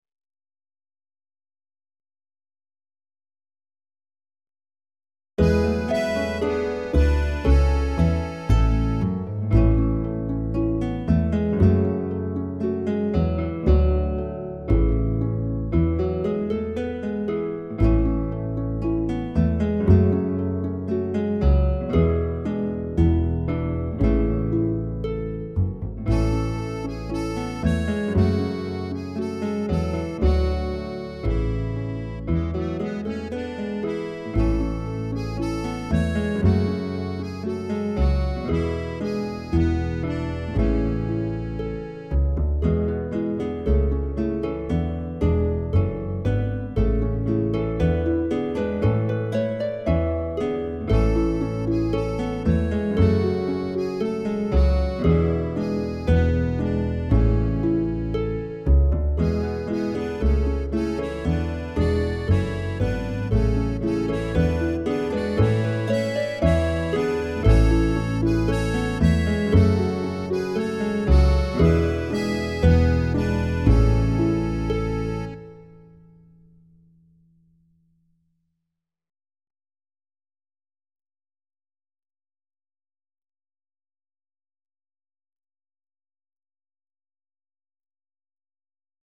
11 Glanbran (Backing Track)